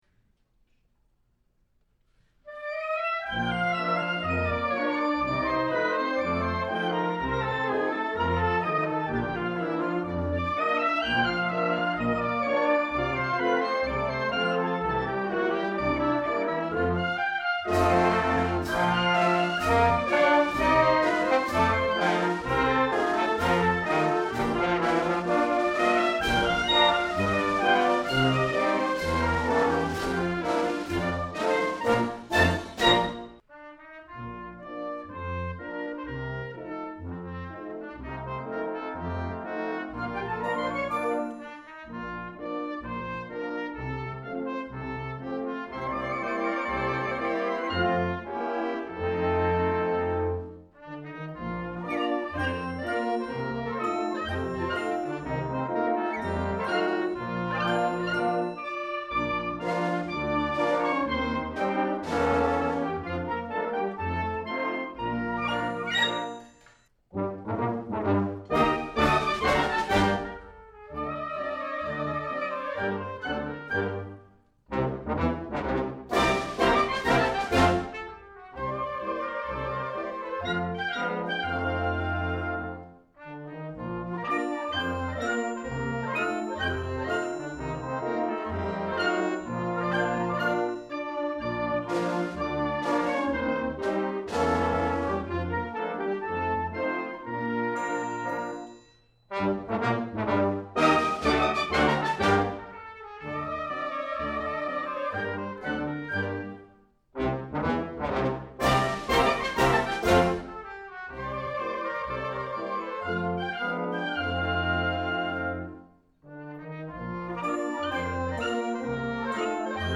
Db Piccolo
C Flute
Oboe
Bassoon
Eb Clarinet
Bb Clarinet
Eb Cornet
Bb Cornet
Trombone
Euphonium
Tuba
Percussion:
using a pair of Oktava MK-12 omnidirectional